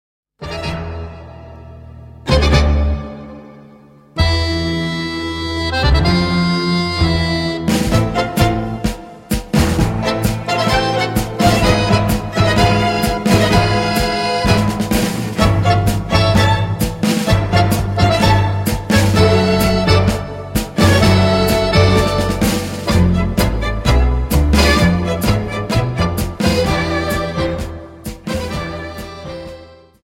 Dance: Tango 32 Song